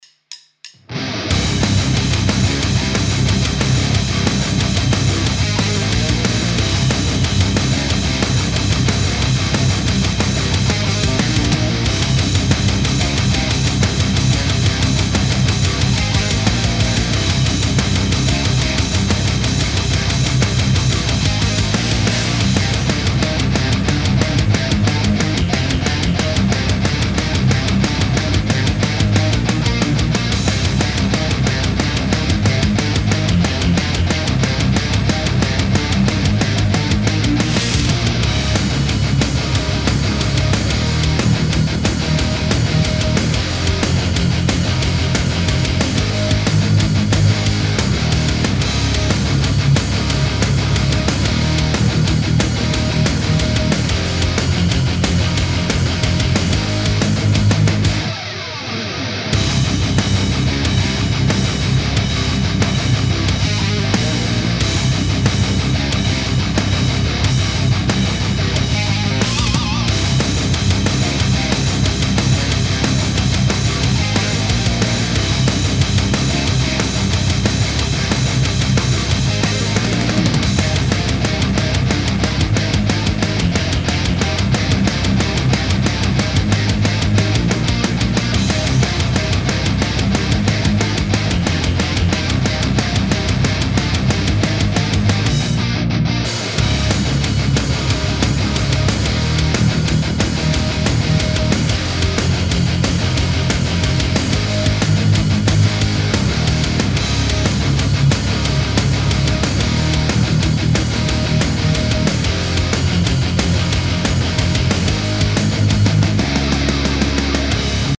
now I was going for a heavily saturated tone (I know, thta means less tight and a bit fizzy) to get a bit more into the death metal realm....
saturated Recto